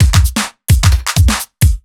OTG_Kit 3_HeavySwing_130-A.wav